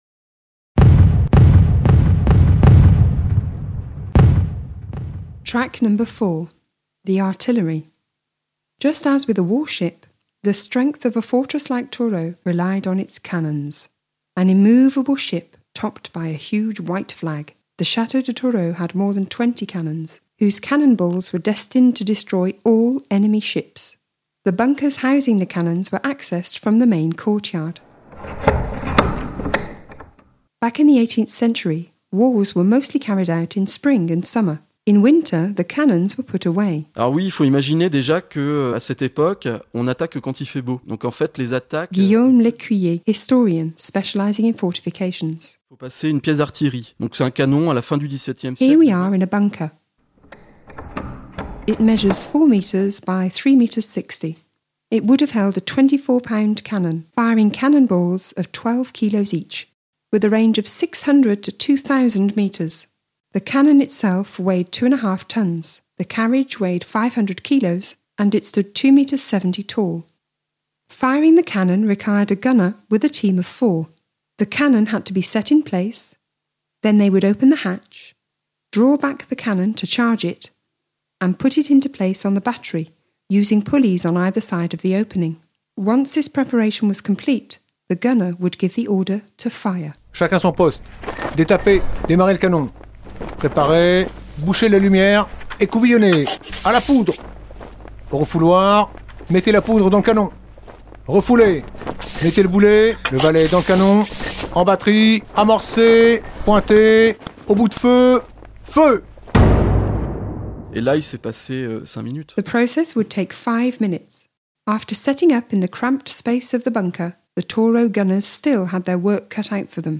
Audioguides